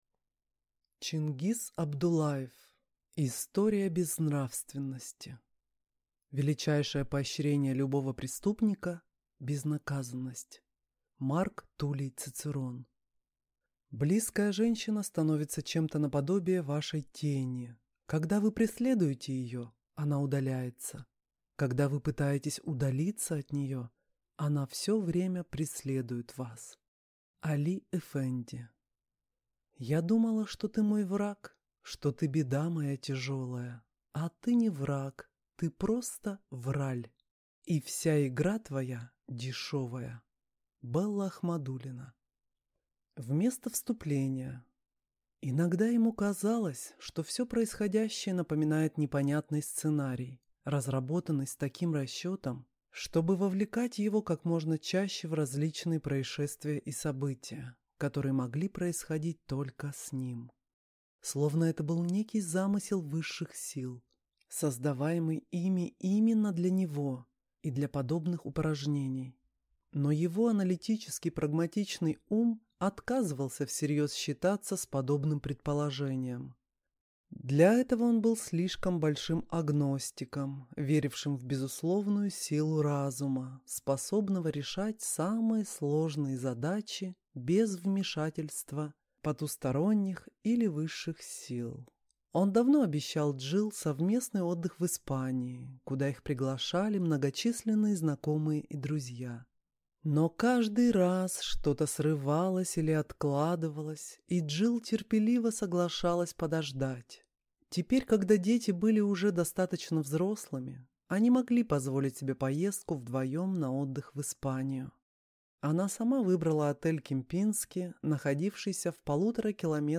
Аудиокнига История безнравственности | Библиотека аудиокниг